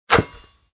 Sound Buttons: Sound Buttons View : Basketball Sound Button